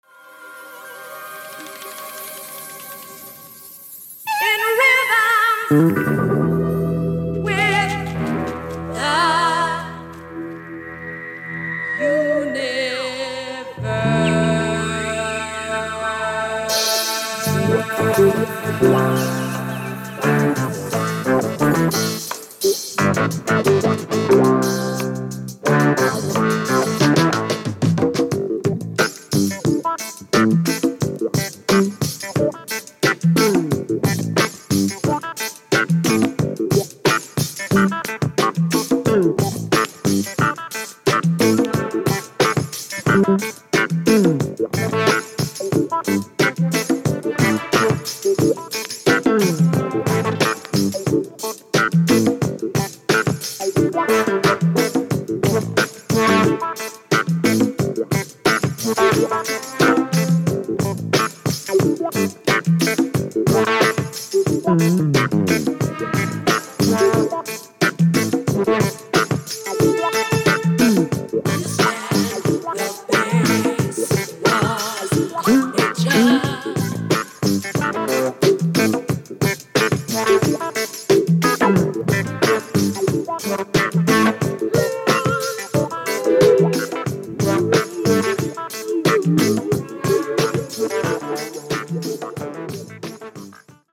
Electric Piano [Rhodes]
Percussion
Drums